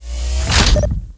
turret_deploy.wav